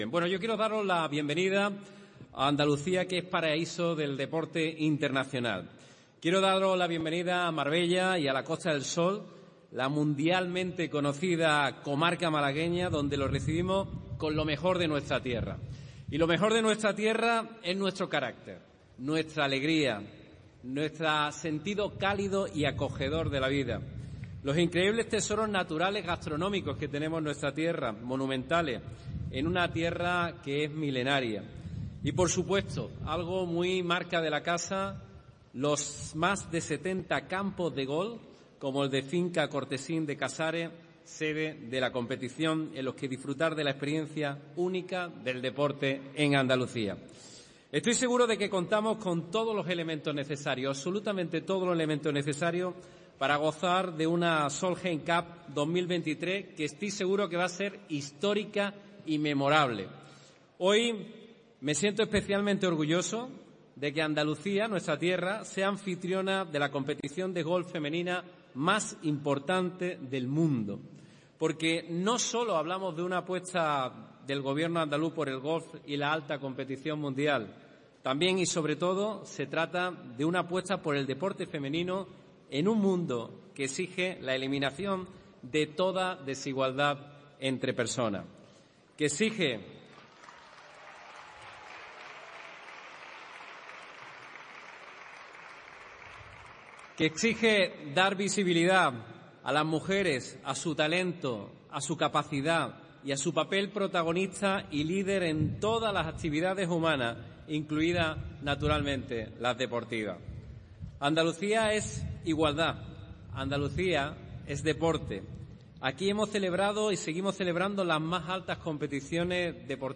En su intervención en Marbella en la ceremonia de inauguración de la Solheim Cup de Golf, Moreno ha dicho sentirse «especialmente orgulloso de que Andalucía sea anfitriona de la competición de golf femenina más importante del mundo» y ha señalado que «no solo hablamos de la apuesta del Gobierno andaluz por el golf y la alta competición sino también, y sobre todo, se trata de una apuesta por el deporte femenino en un mundo que exige la eliminación de toda desigualdad entre personas».